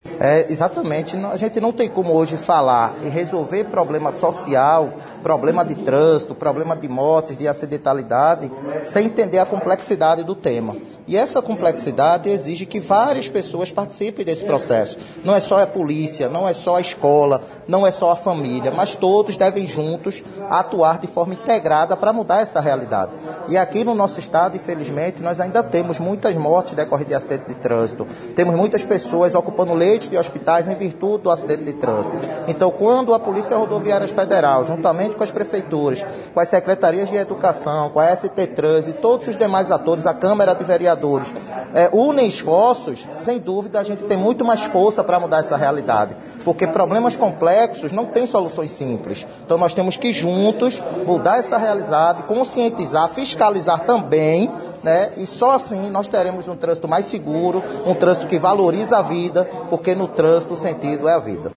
Aconteceu na manhã desta sexta-feira (26) na sede da Delegacia da PRF Patos a assinatura de um Termo de Adesão e Compromisso entre a Prefeitura de Patos, Polícia Rodoviária Federal e Secretaria Municipal de Educação, ao Projeto EDUCAR PRF.